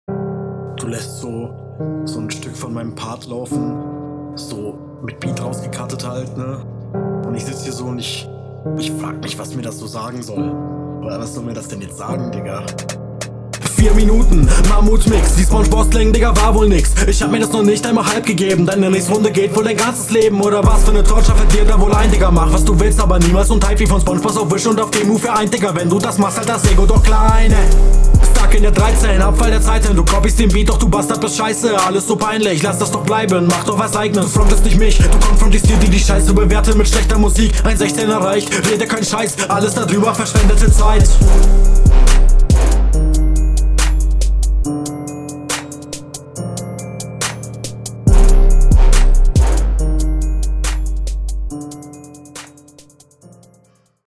Flow geht ab digga